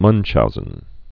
(mŭnchouzən, mŭnchhou-)